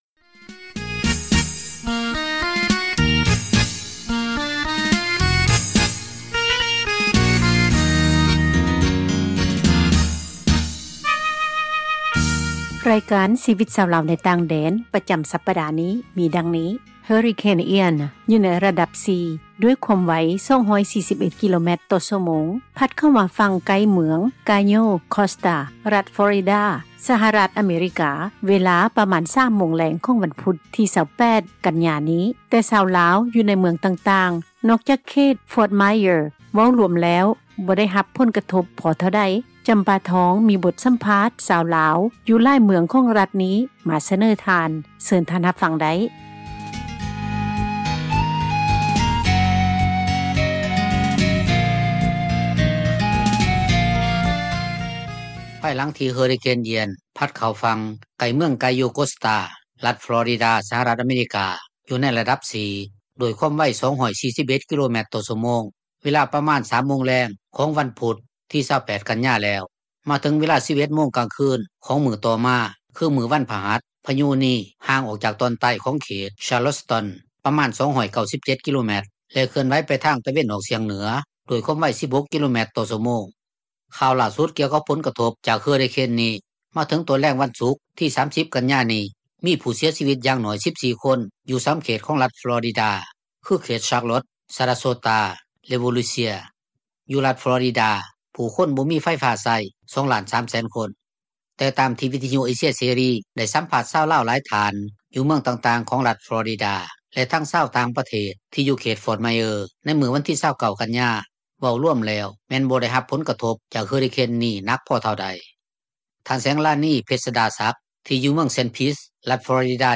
ມີບົດສັມພາດ ຊາວລາວ ຢູ່ຫລາຍເມືອງ ຂອງຣັຖນີ້